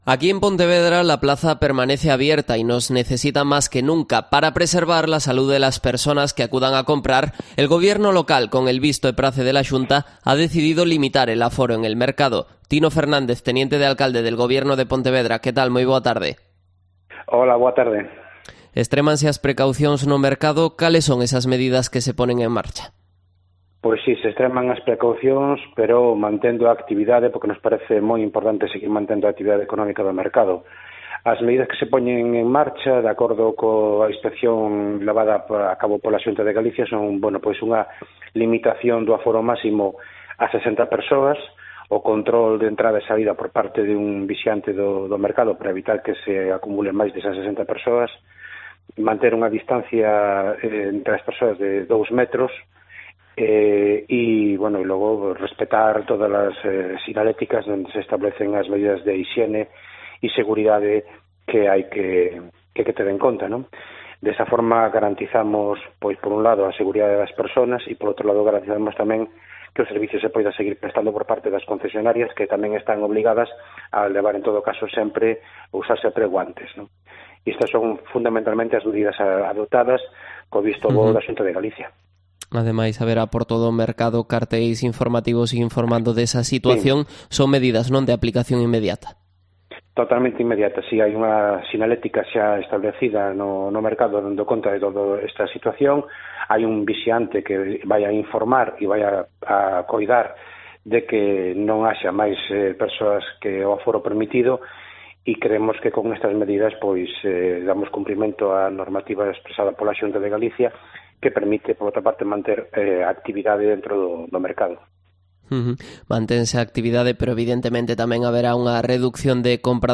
Entrevista a Tino Fernández, teniente de alcalde de Pontevedra